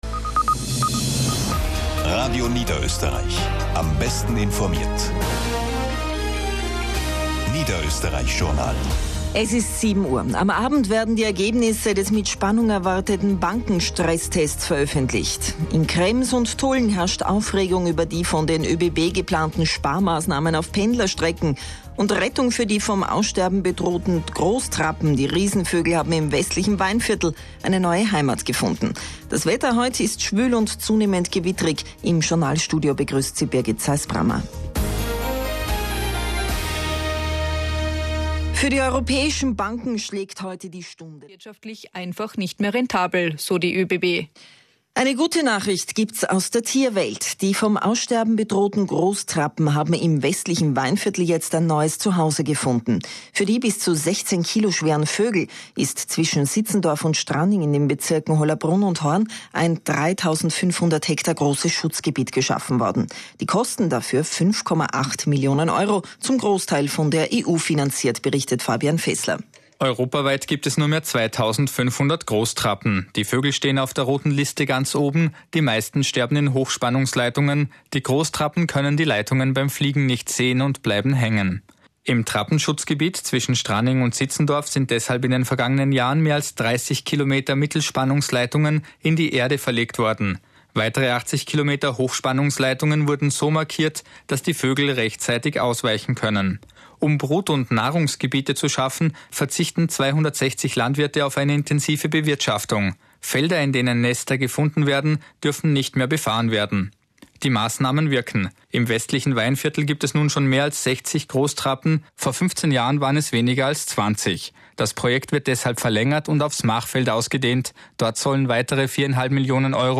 Radio Niederösterreich Nachrichten-Beitrag am 23. Juli 2010 (mp3, 2,31 MB)